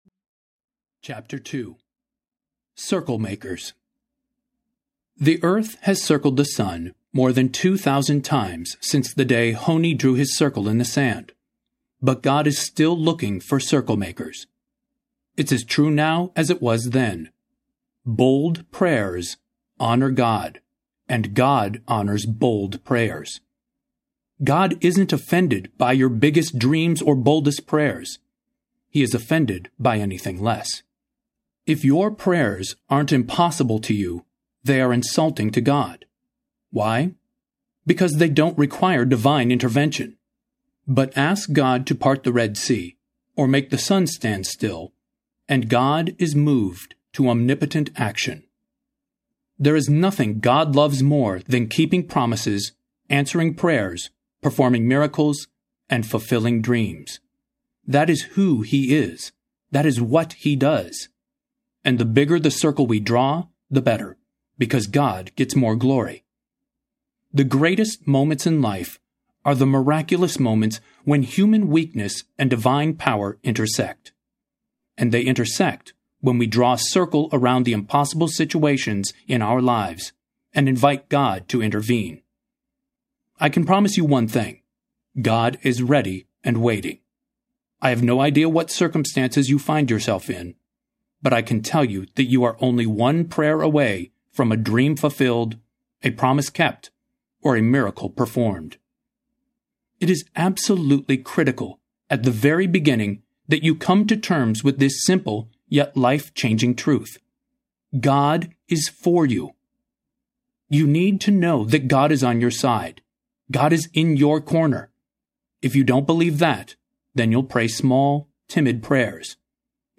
Narrator
7.0 Hrs. – Unabridged